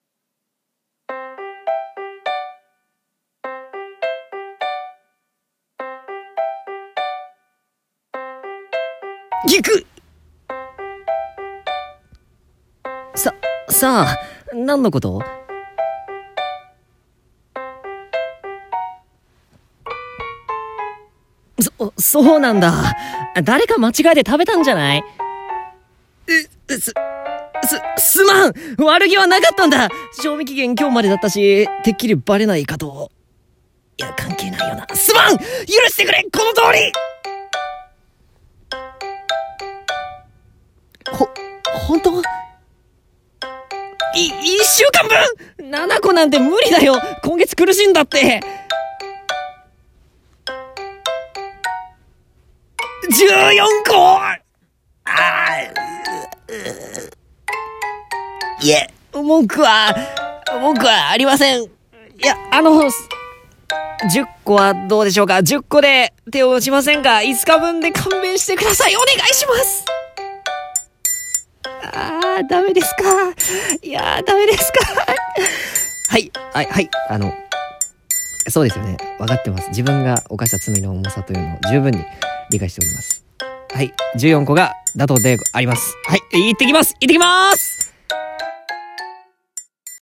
【ギャグ声劇台本】